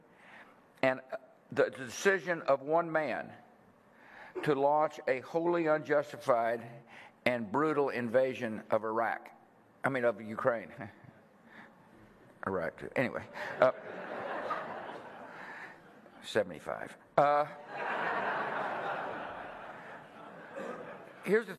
George W. Bush bei einer Rede in Texas